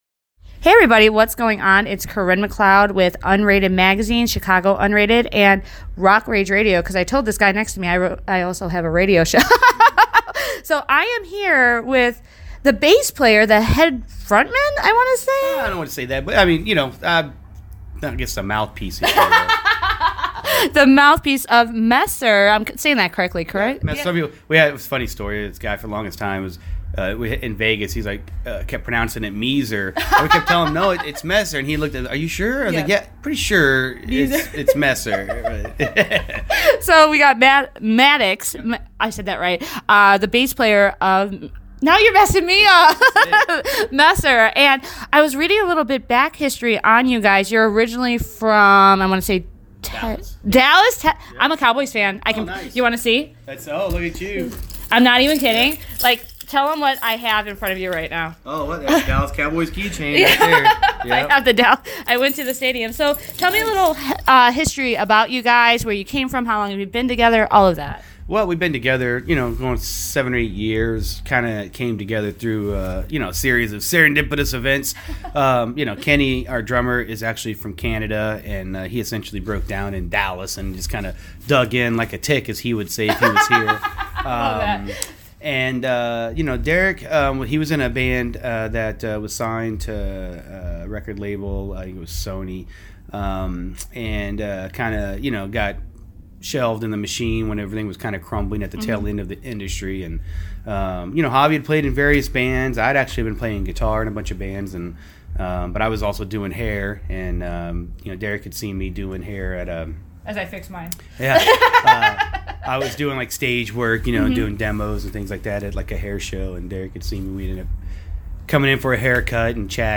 At Rochause, West Dundee, Il, USA